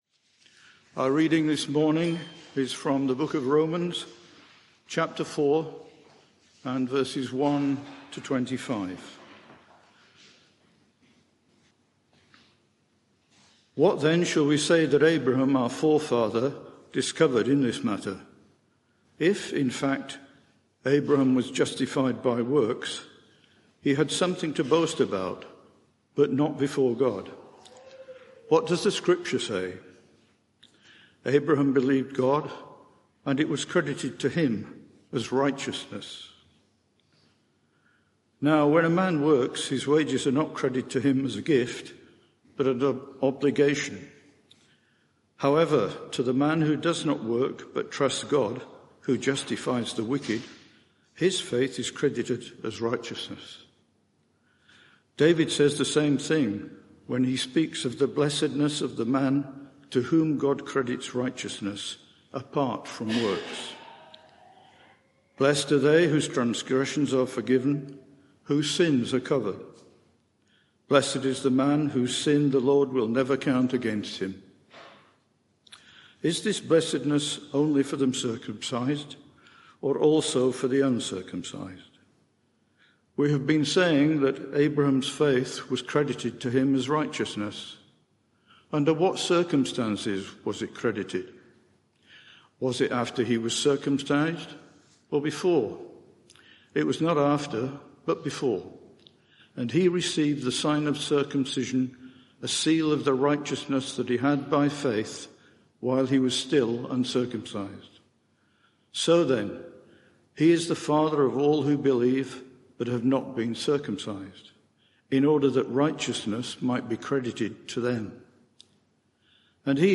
Media for 11am Service on Sun 22nd Oct 2023 11:00 Speaker
Sermon